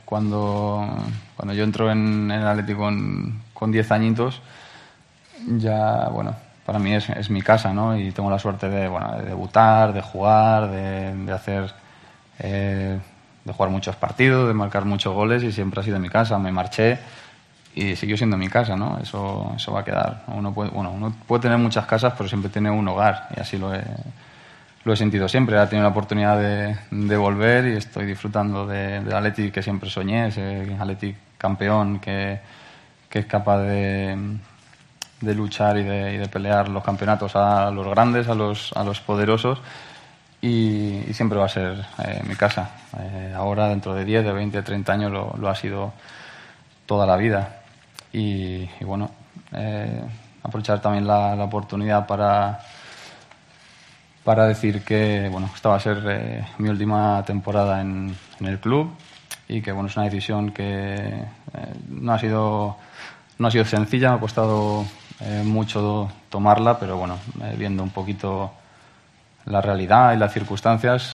El delantero del Atlético de Madrid Fernando Torres anunció en un acto publicitario que esta será su última temporada en el club colchonero y que espera terminar su etapa celebrando un título.